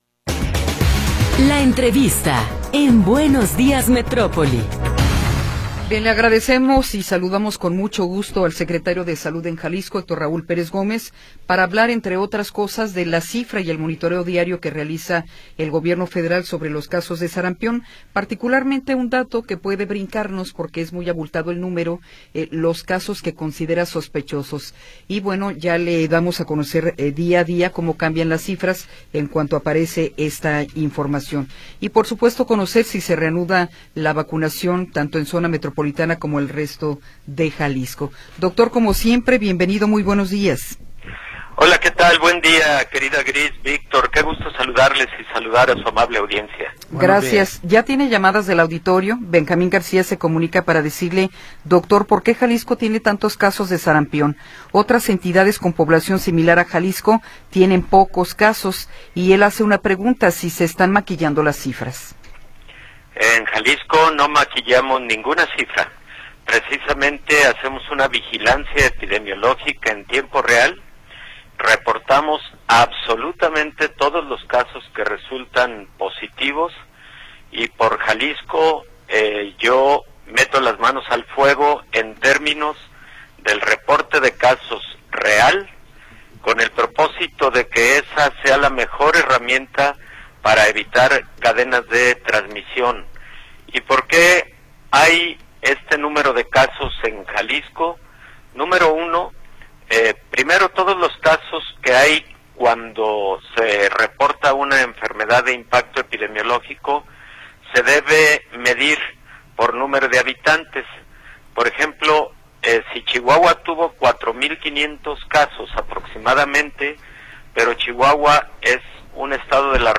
Entrevista con el Dr. Héctor Raúl Pérez Gómez
ENTREVISTA-1.m4a